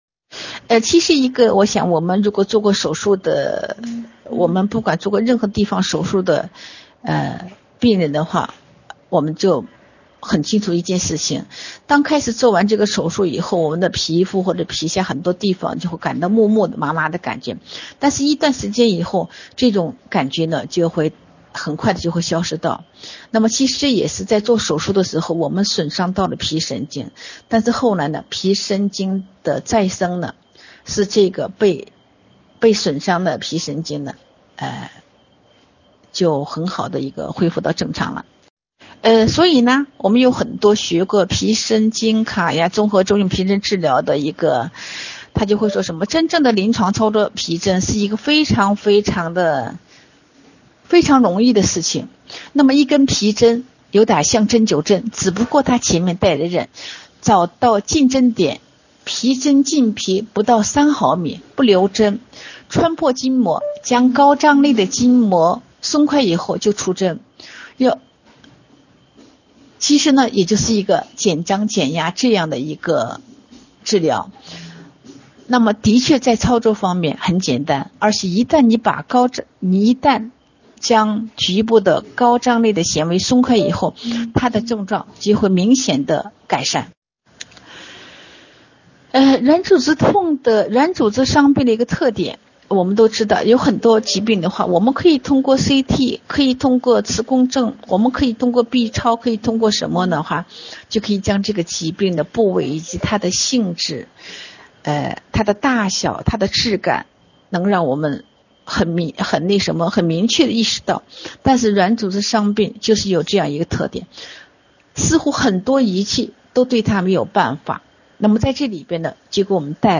Hier finden Sie unsere veröffentlichten TCM-Vorträge unserer chinesischen Professoren (in chinesischer Sprache).
铍针与皮神经卡压综合征讲座-2.mp3